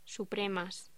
Locución: Supremas
voz palabra sonido